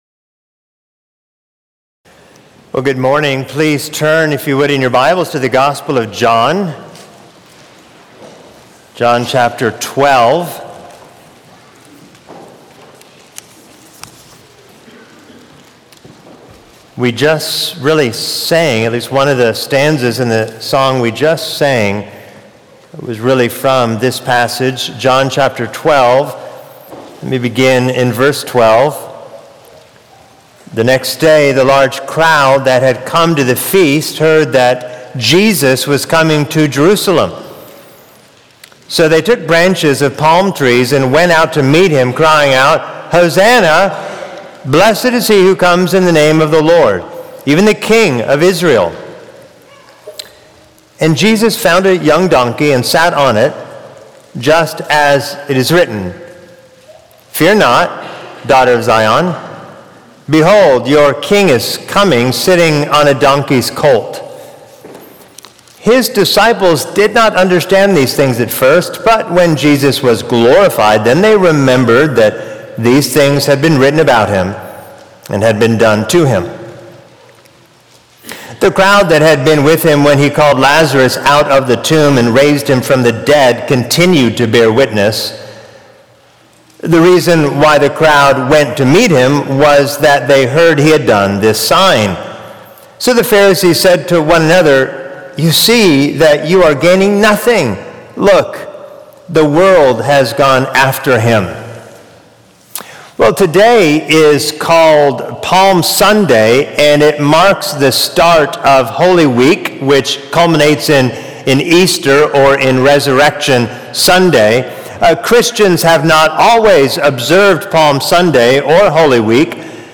The Palm Sunday Sermon - Mount Vernon Baptist Church : Mount Vernon Baptist Church